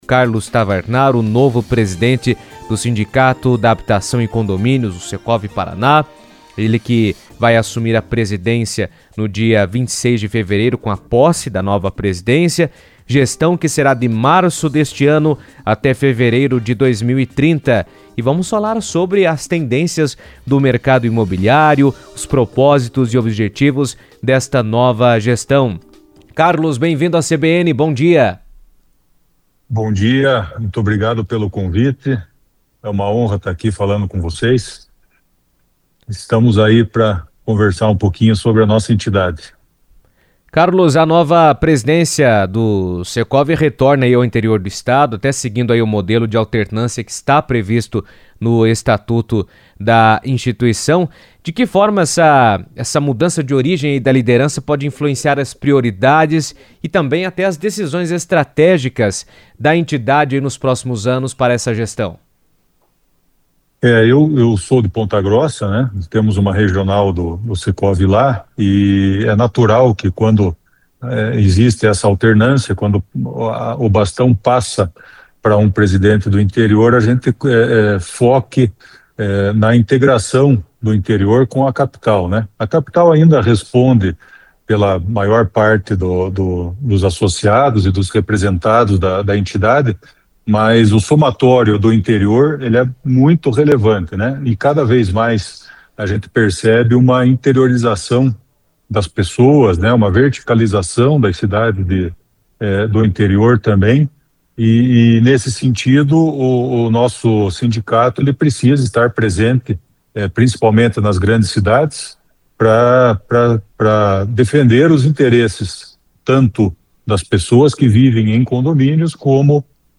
destacou em entrevista à CBN a importância do Interior do estado para o setor imobiliário.